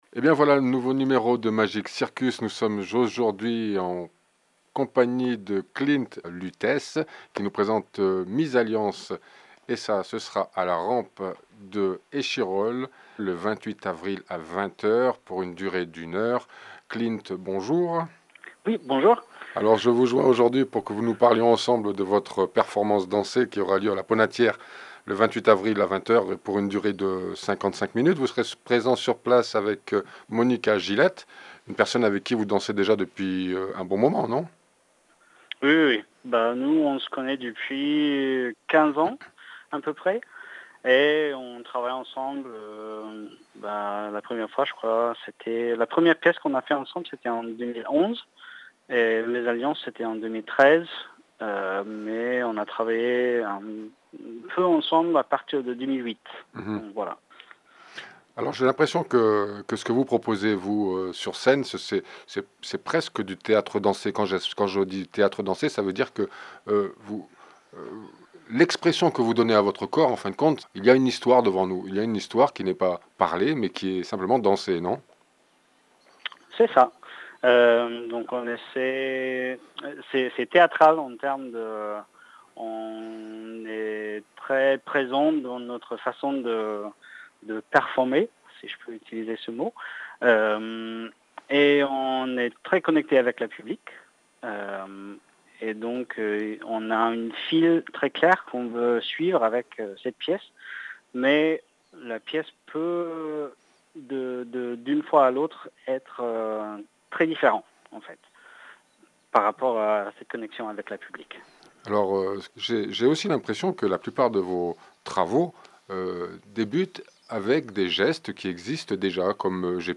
Radio Interview (Magic Circus